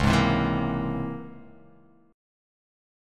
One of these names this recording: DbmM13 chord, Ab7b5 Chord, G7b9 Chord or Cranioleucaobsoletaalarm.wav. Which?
DbmM13 chord